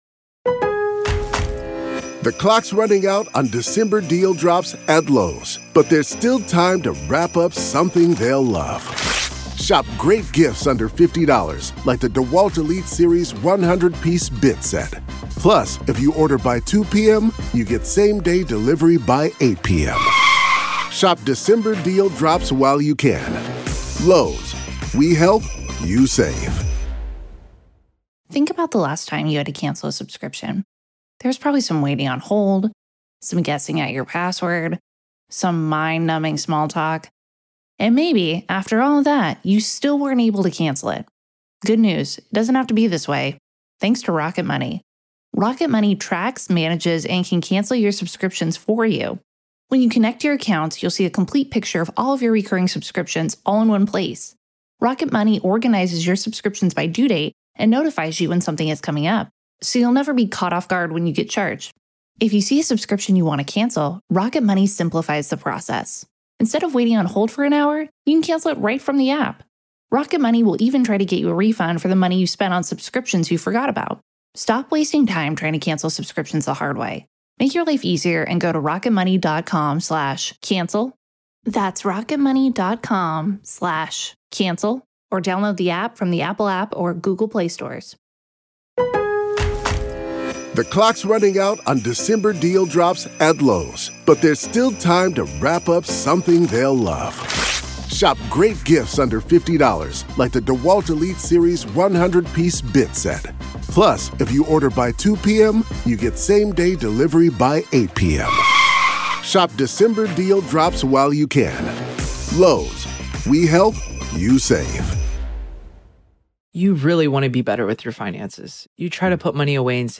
Don't miss a beat of the gripping testimony and explosive evidence as the accused faces life-altering charges for the brutal murder of his own family.